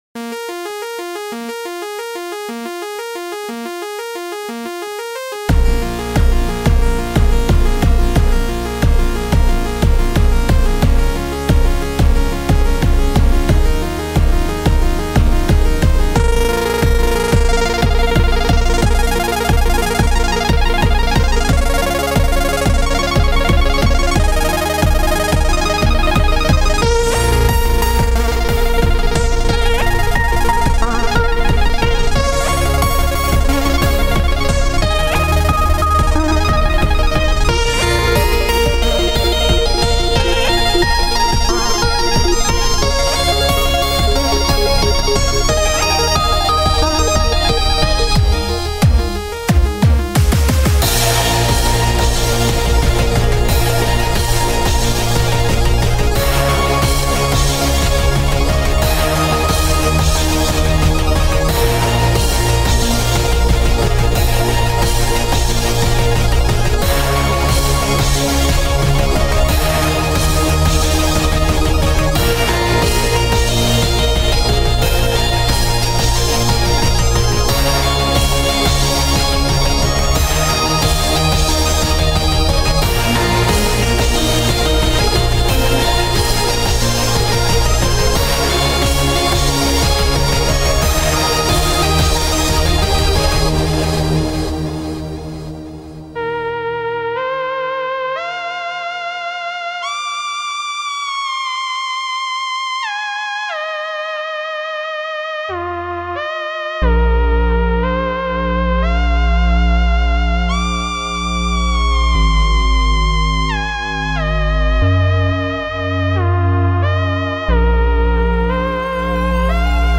This sounded like Allan Parson's Project.